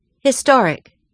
historic /010/ /hi.’stɒr.ɪk/ /ˌhɪ.’stɔːr.ɪk/